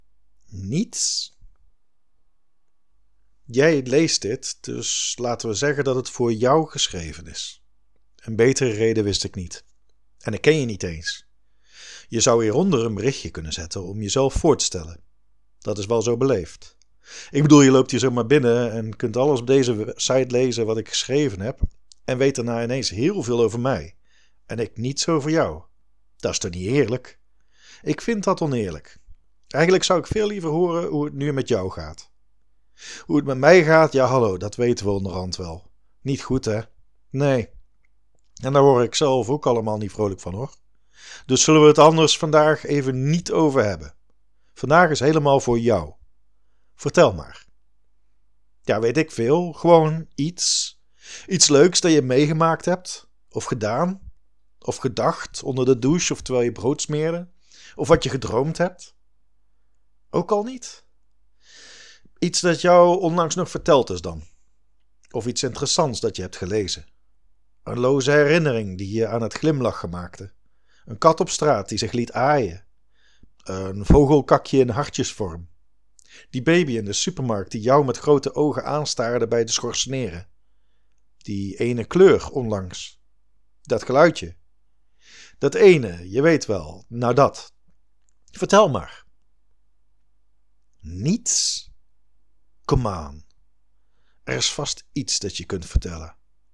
Audio stories Korte proza